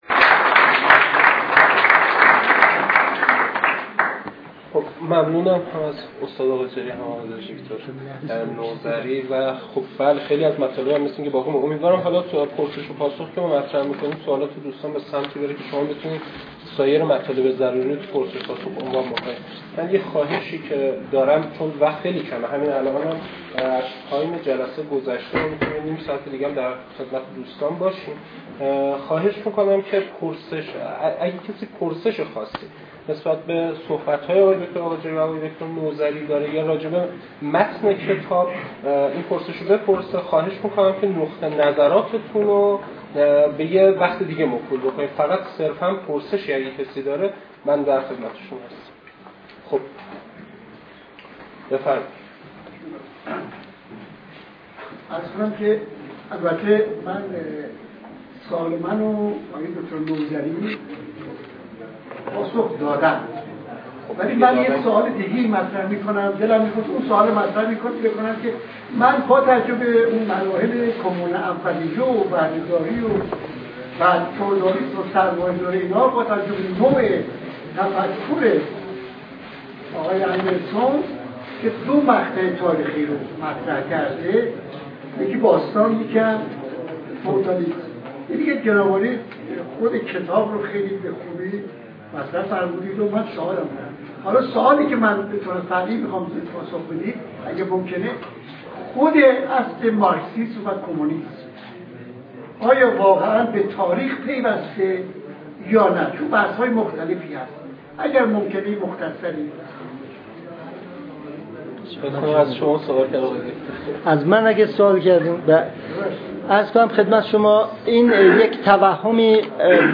پرسش و پاسخ